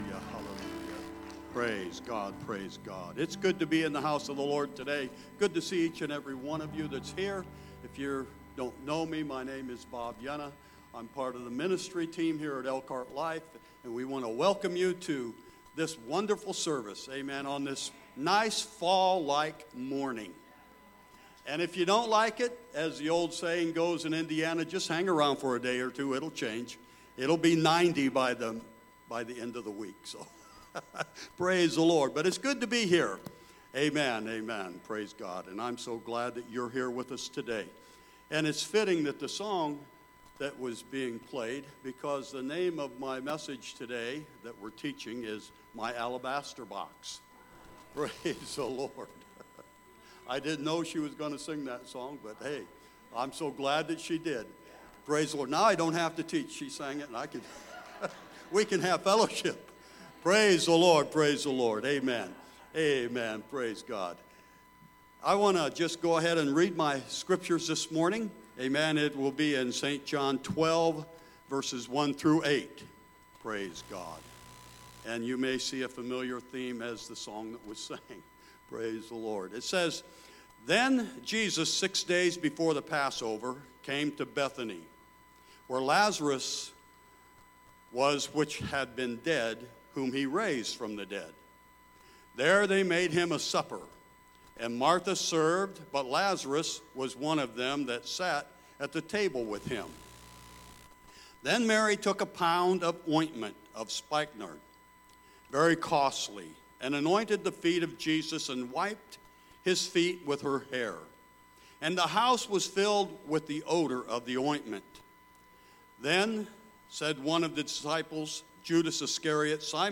Sunday Service My Alabaster Box